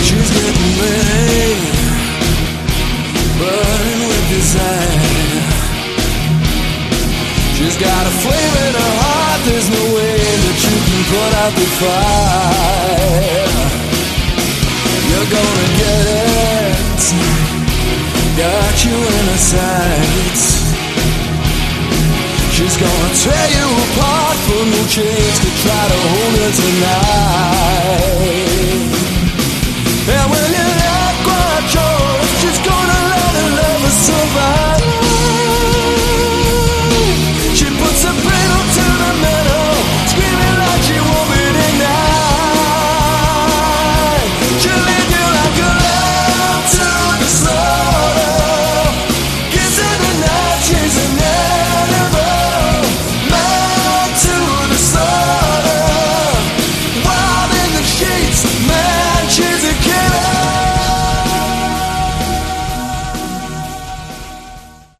Category: AOR
lead and backing vocals
electric and acoustic guitars
drums, percussion
keyboards